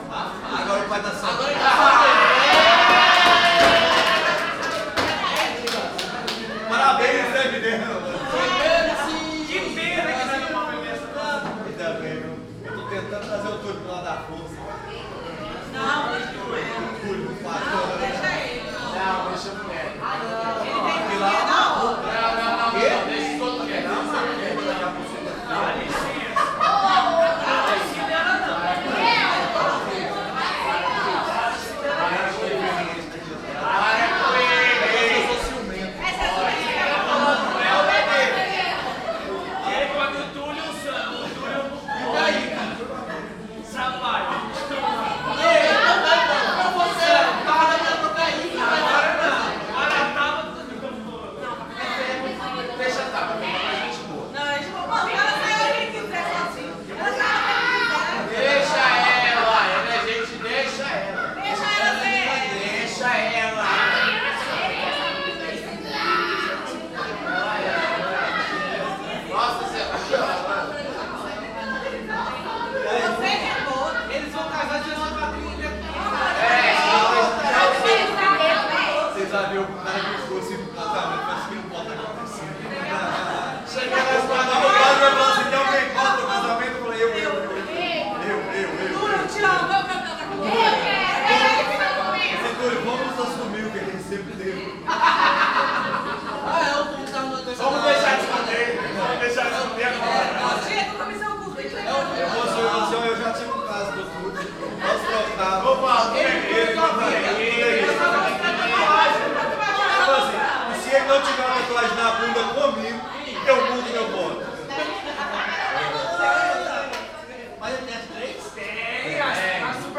psr-brazuca-bar.mp3